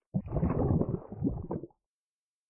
walkslosh7.ogg